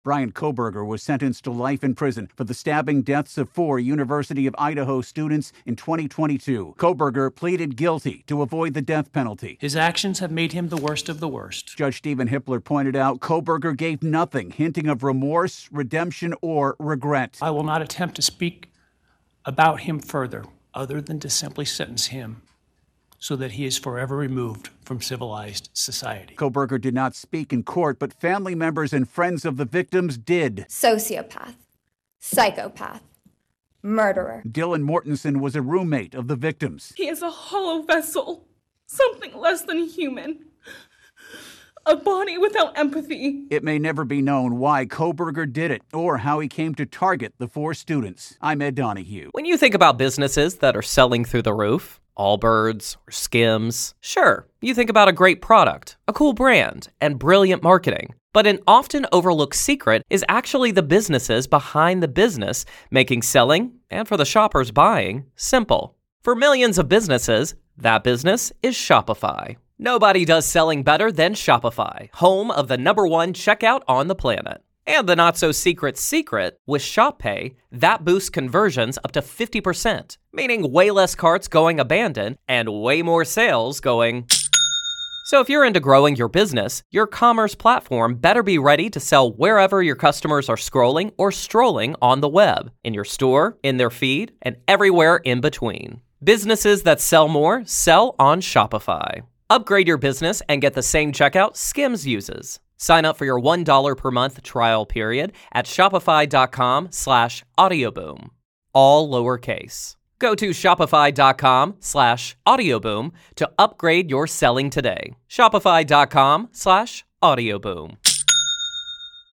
reports on sentencing for the man who pleaded guilty to killing 4 college students.